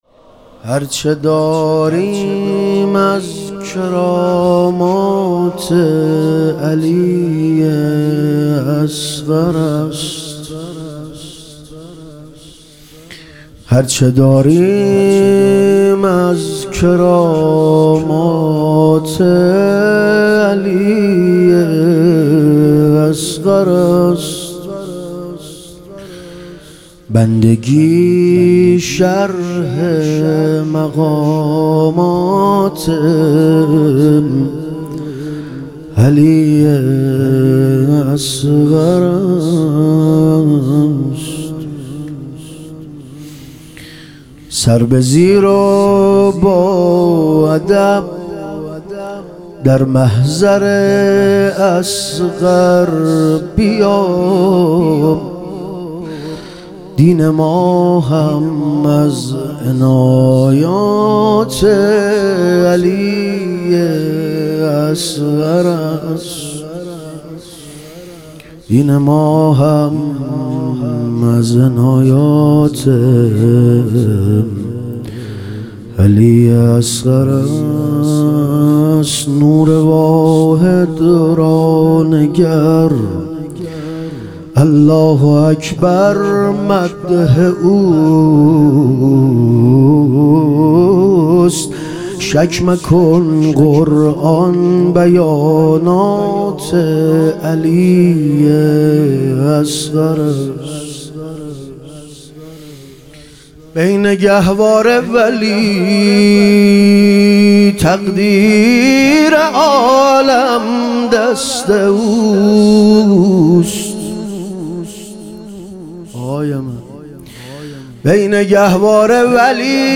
مدح و رجز